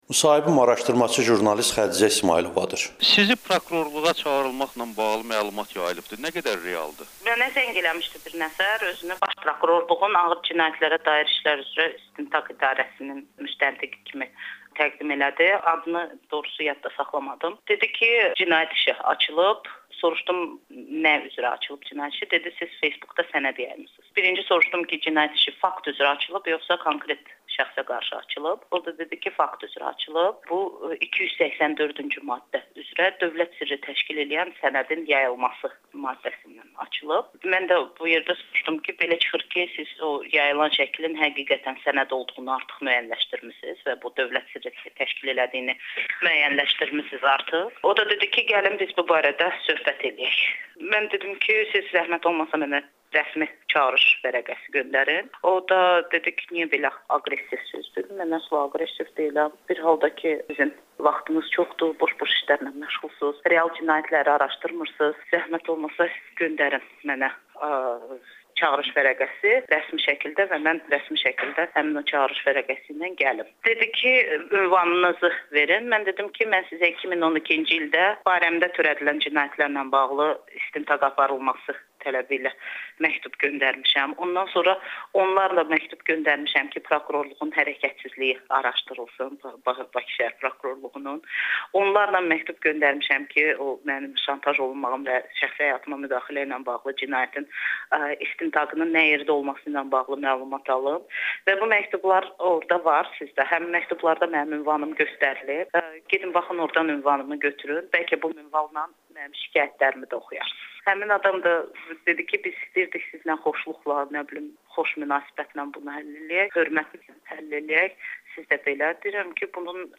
[Audio-müsahibə]
Araşdırmaçı jurnalistin Amerikanın Səsinə müsahibəsi
Xədicə İsmayılova ilə müsahibə